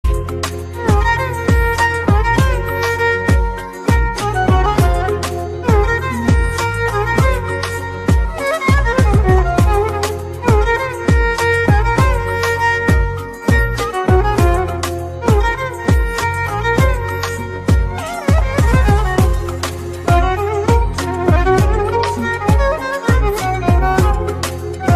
Kategori Elektronik